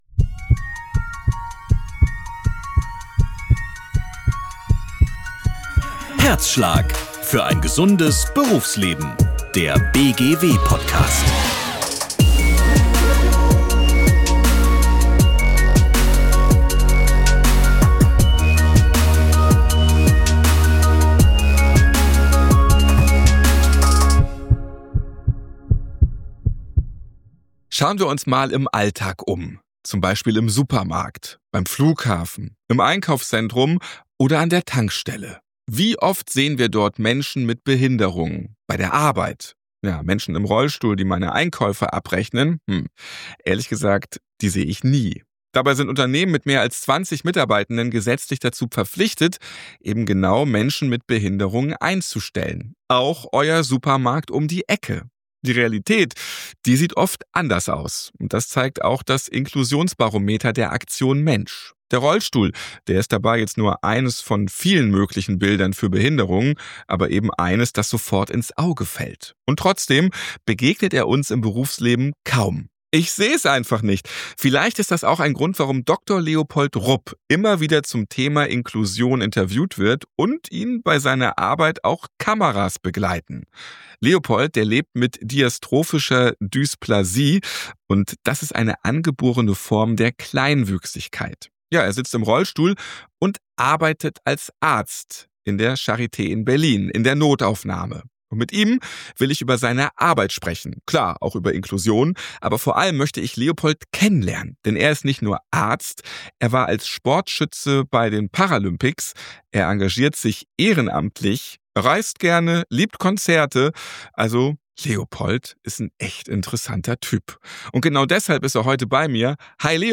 Ein inspirierendes Gespräch über Barrieren im Gesundheitswesen, selbstbestimmtes Leben, die Macht von Vorbildern, strukturelle Hindernisse im Alltag – und warum Inklusion kein "Trotzdem", sondern ein "Natürlich" sein sollte.